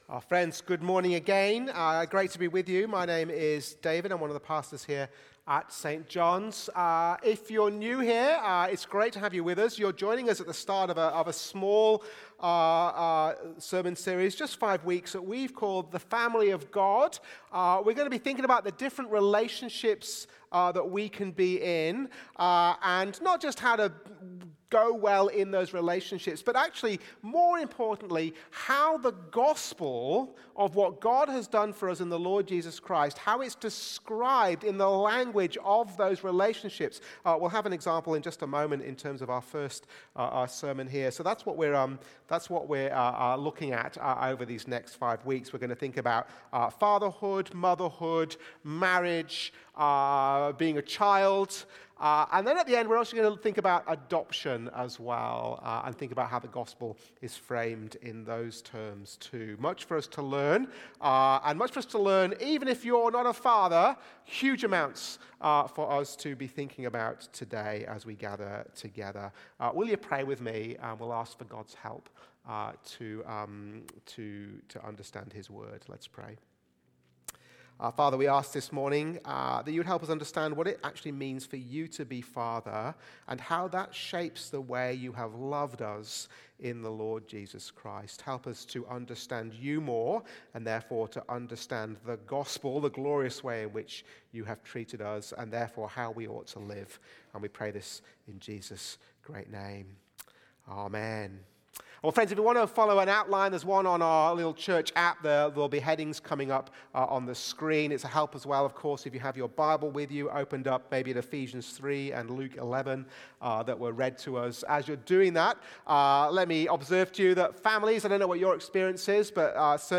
Watch the full service on YouTube or listen to the sermon audio only.